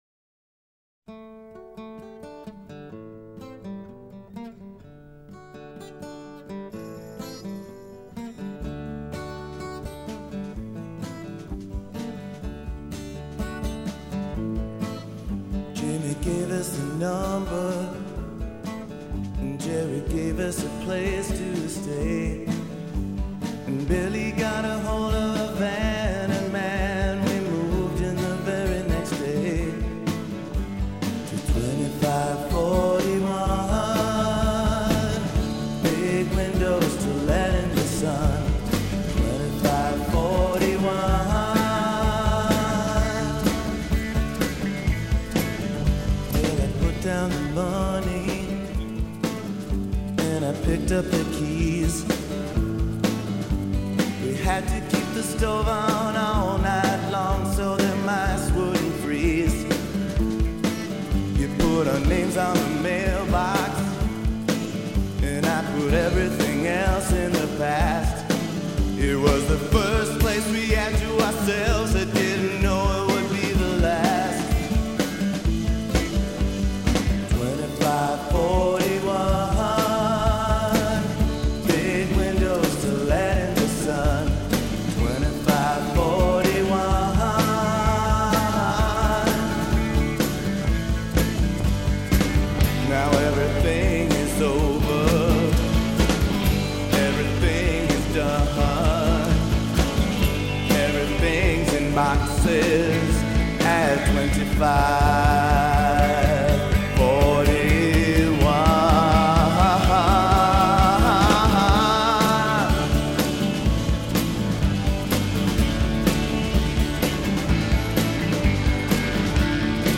is a downcast story song heavy on the melody